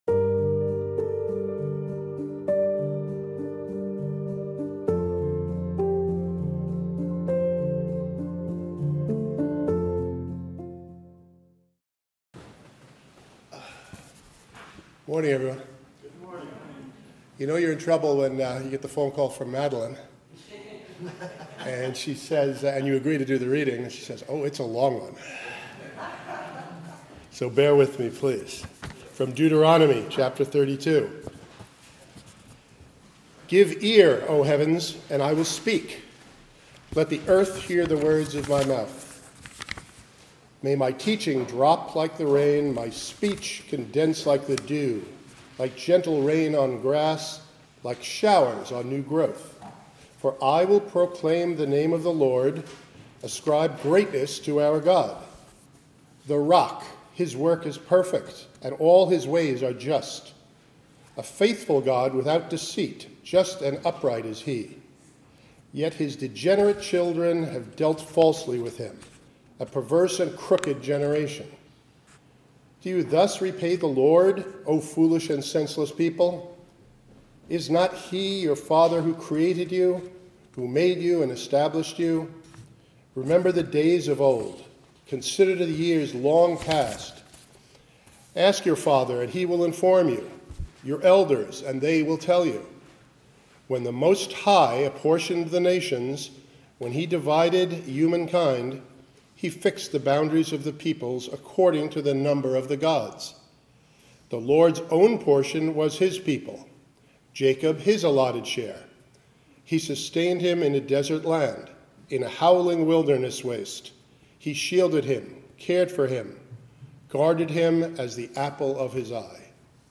Sermons | Community Church of Douglaston